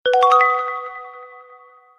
status_notification.ogg